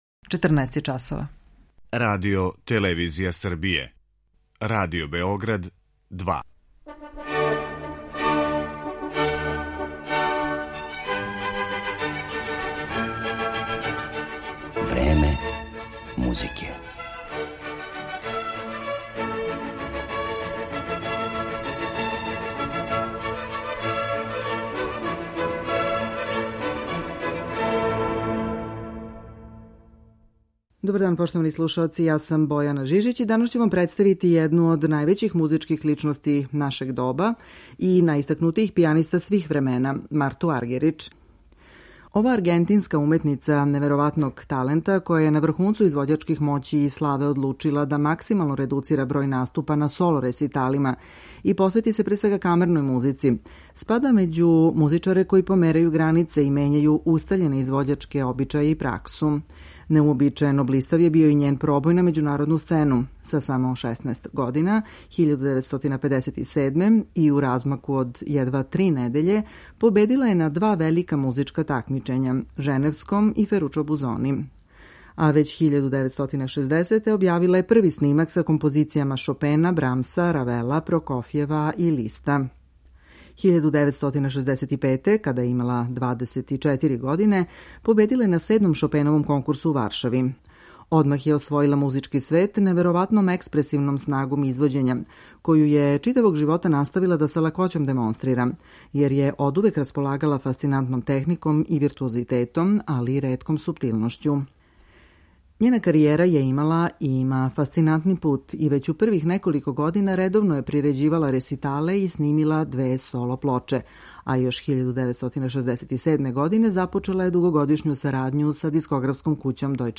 Слушаћете је и као солисту и у сарадњи са другим музичарима